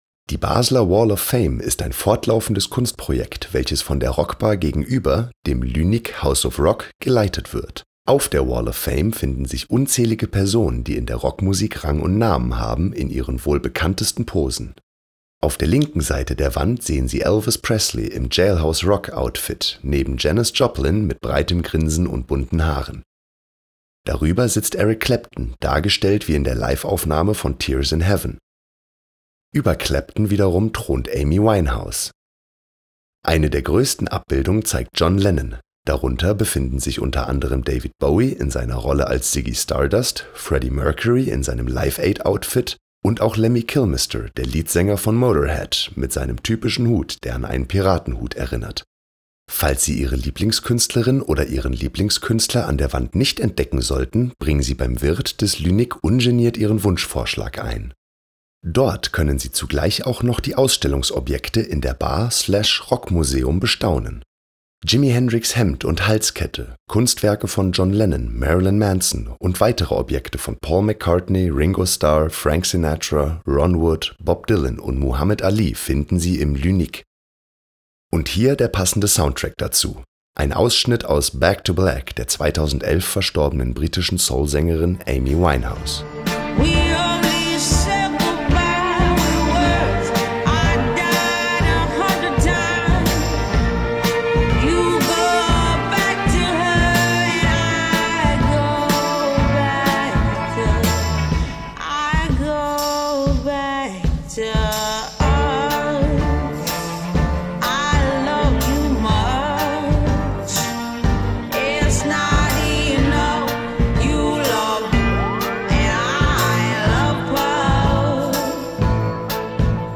QUELLEN der Musikbeispiele: